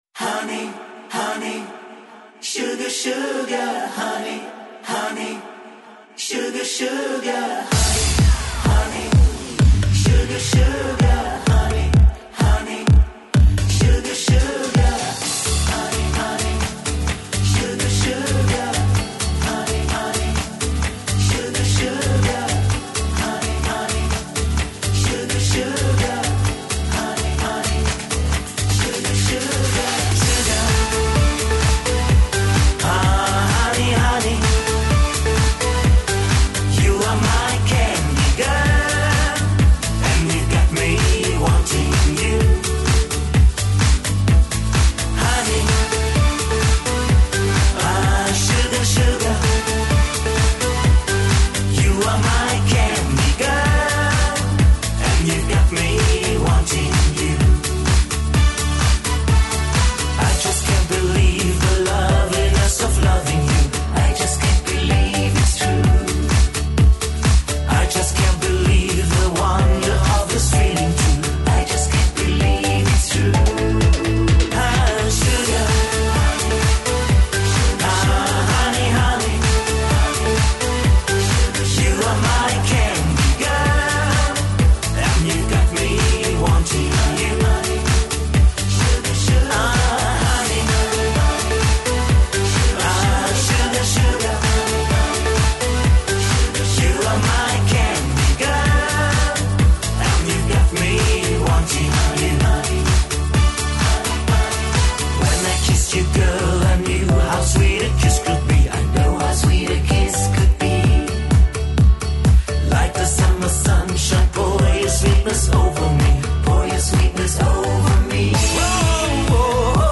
Dance-Eletronicas